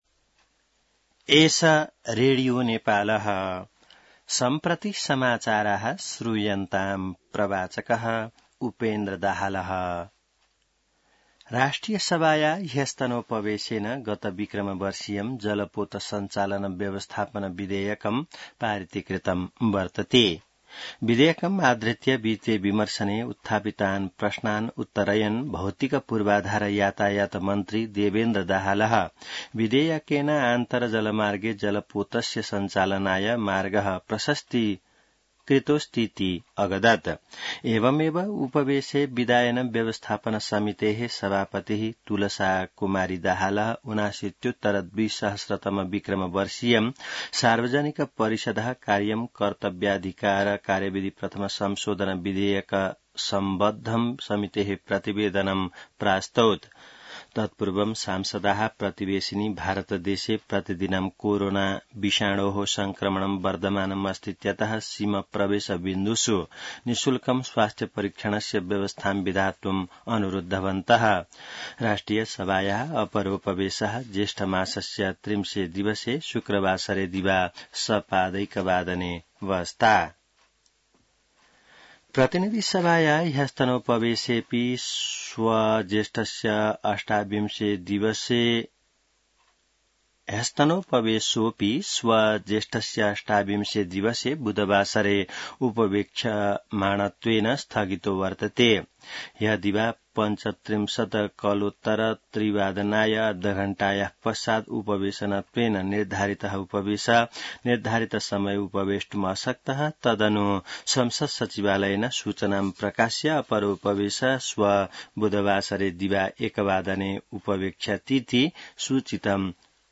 संस्कृत समाचार : २७ जेठ , २०८२